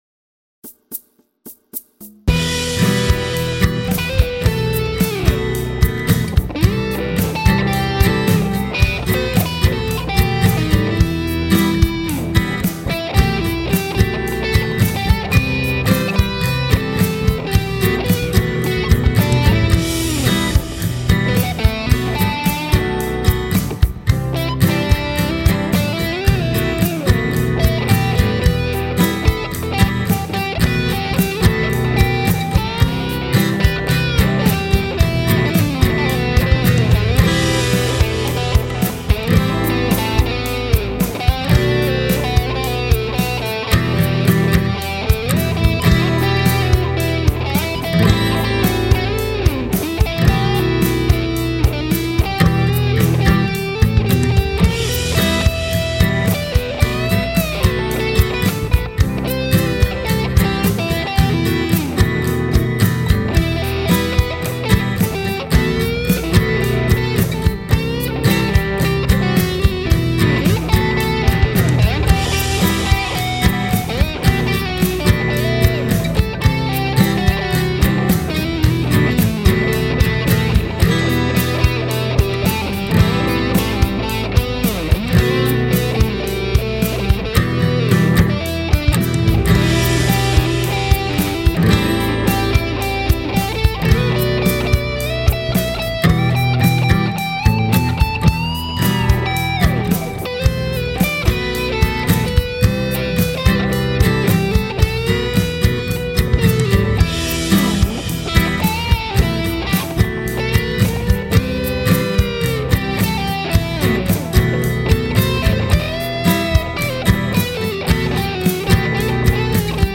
One, the chord sequence is: Em; B7; C; B7 for the verse. Chorus is: Em; Am; B7.
Hasn’t stopped me from figuring scales I CAN use, E minor pentatonic, with the E minor chromatic scale superimposed here and there to match the chord being played, ( the D sharp of the B7, F sharp) gives me the feel of the song,which is a Latin Rock type of tune.
E Harmonic minor.